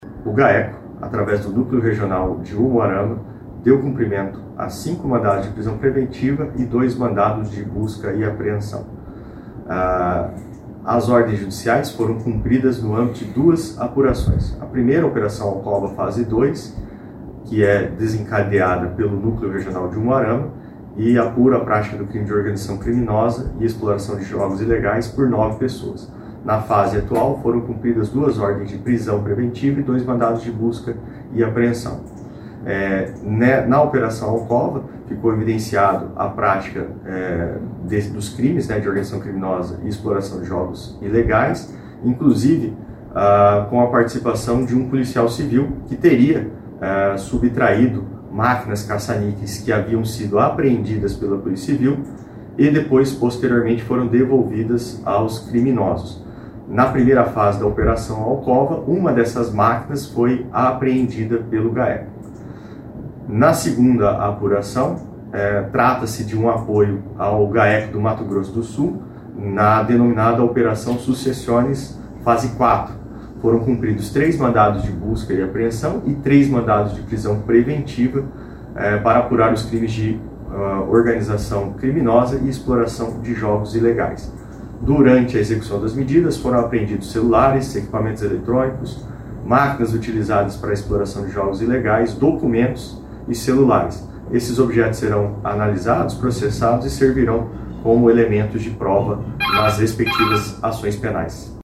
Ouça o que diz o promotor de Justiça Guilherme Franchi da Silvas: